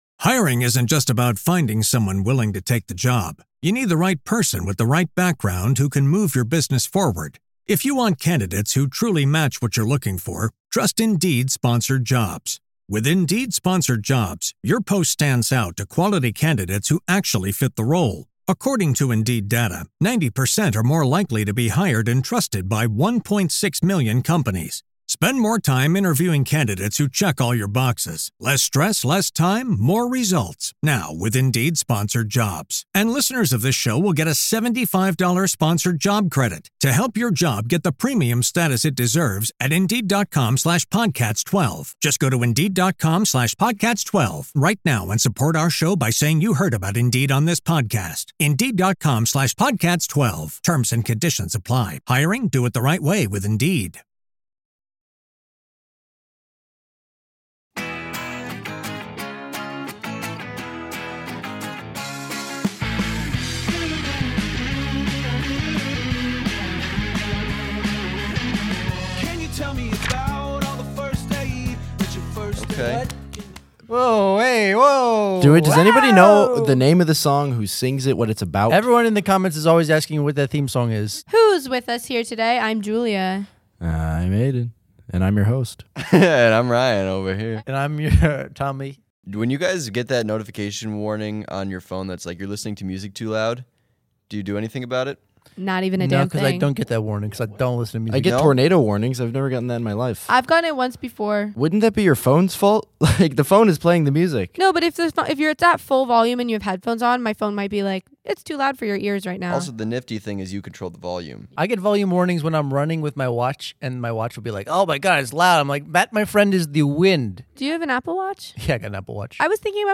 Delve into the depths of Greek tragedy with a comedic twist as the "We Didn't Read It" crew improvises their way through Sophocles' "Oedipus Rex," proving that even ancient family drama can be side-splittingly funny.